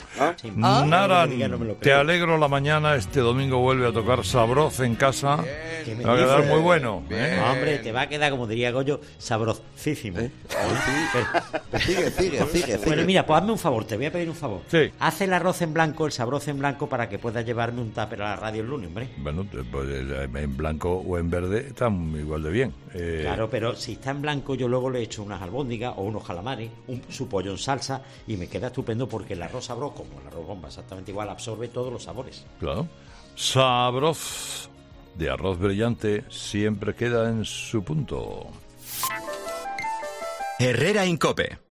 Aunque esta receta es tan fácil, Carlos Herrera te resuelve las dudas sobre cómo preparar el arroz blanco perfecto